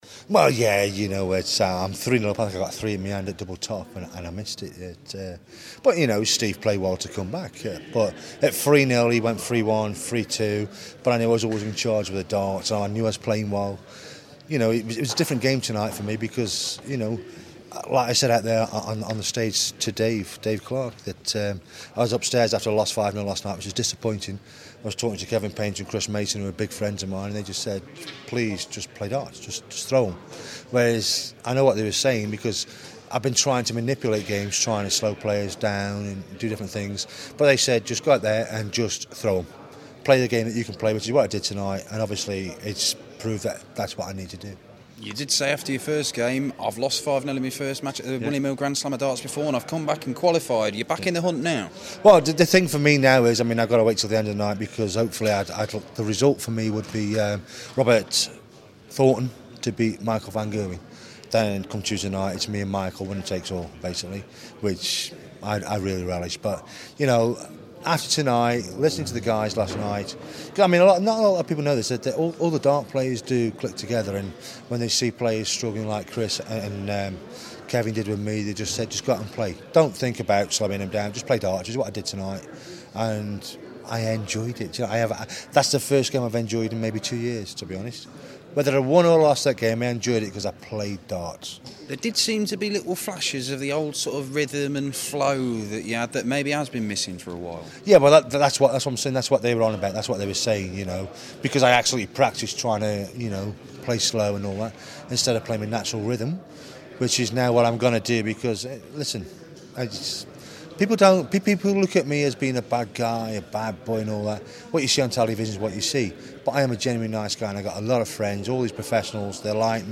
William Hill GSOD - Hankey Interview (2nd game)